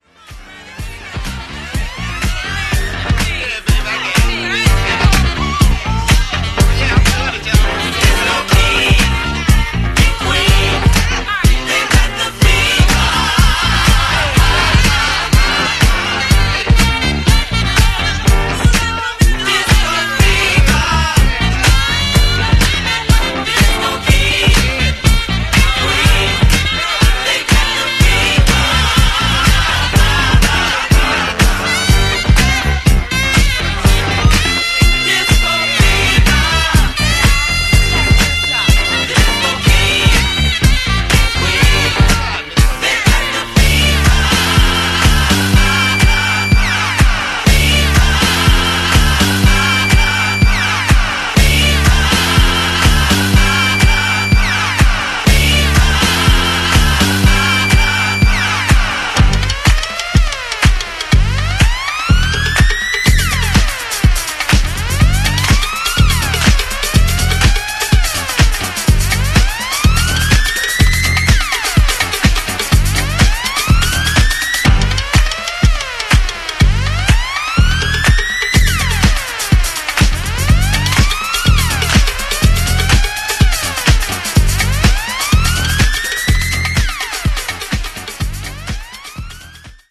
執拗な弄りは抑えめで、原曲を尊重した仕上がりとなっています。